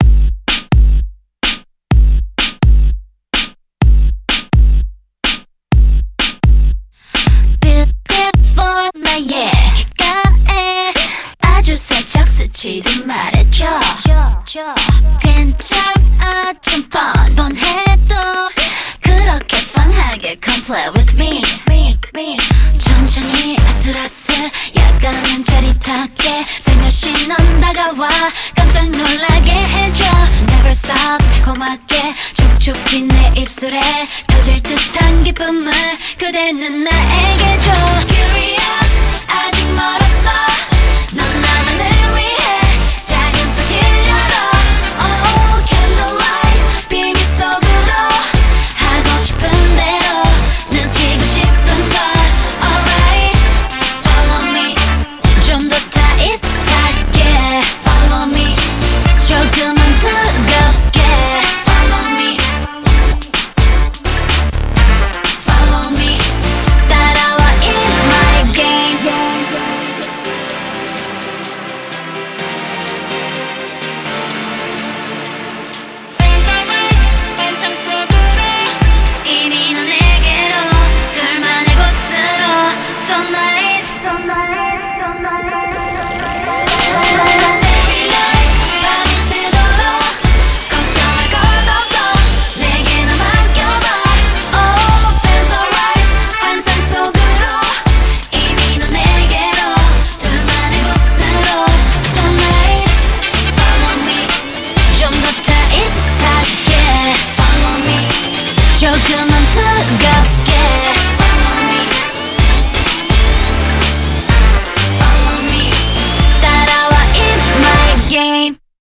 BPM124
Audio QualityPerfect (High Quality)